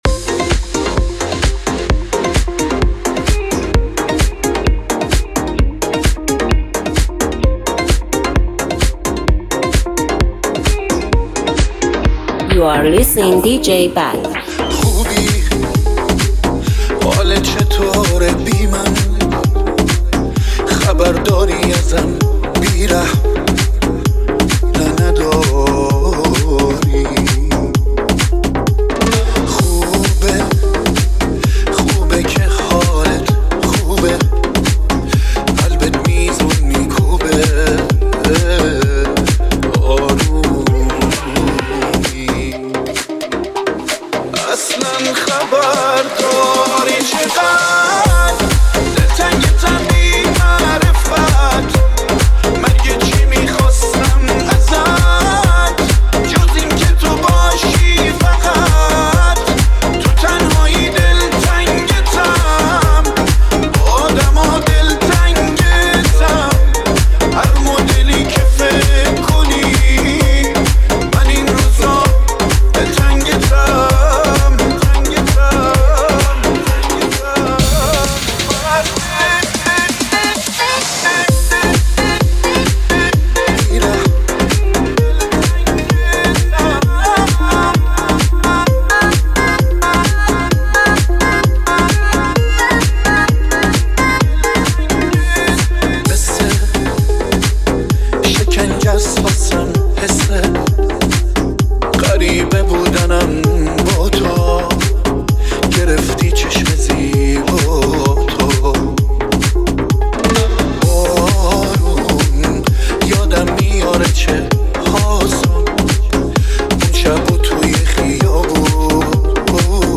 ریمیکس و بیس دار
غمگین